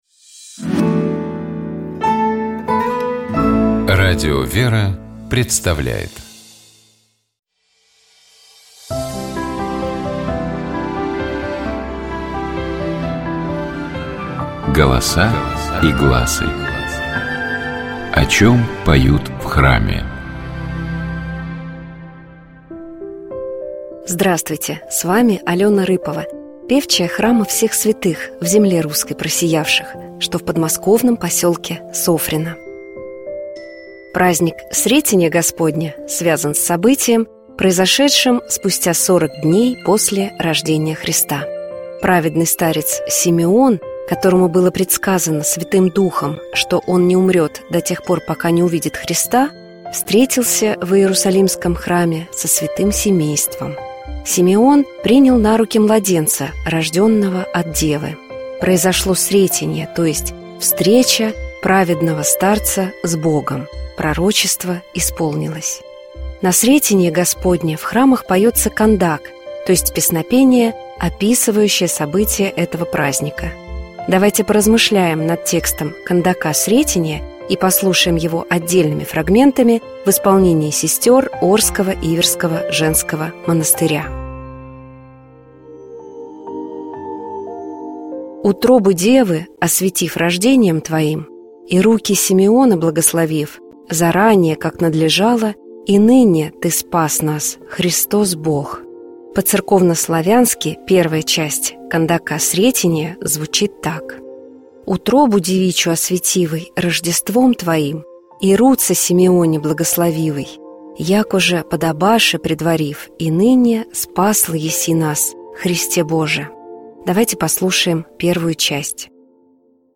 Это была Херувимская песнь, которая символизирует служение ангельских сил, херувимов, у Божьего Престола. Давайте поразмышляем над текстом Херувимской песни и послушаем её отдельными фрагментами в исполнении сестёр Орского Иверского женского монастыря.
Первая часть — протяжная, медленная, вторая — в более быстром темпе.